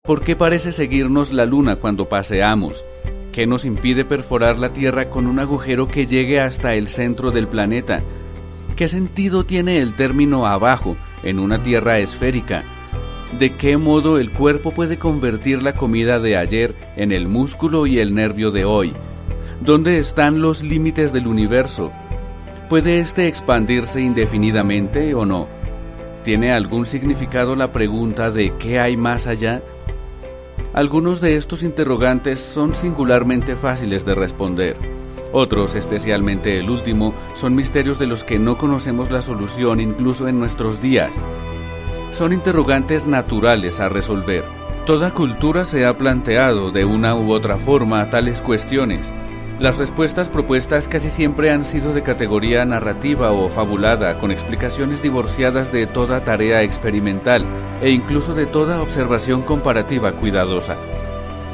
Voice over para e-Learning y documentales, estilo Nat-Geo.
kolumbianisch
Sprechprobe: eLearning (Muttersprache):